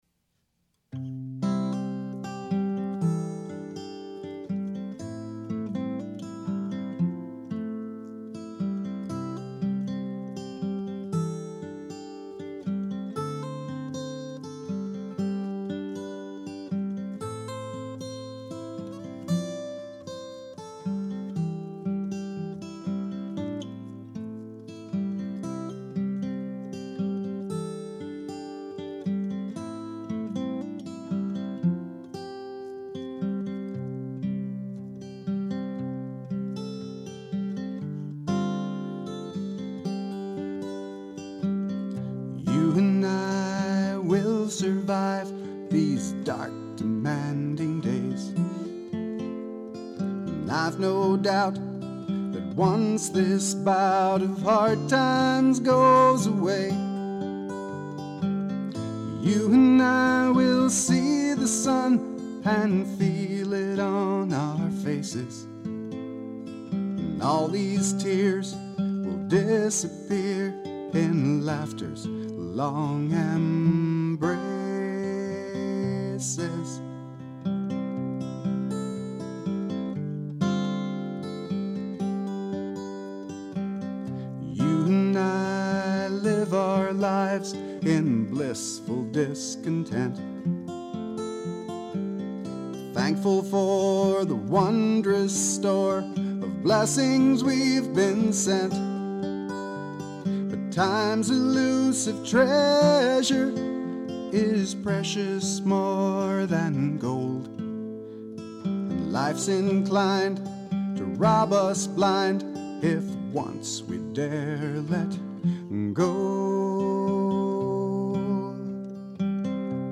Guitar & Vocals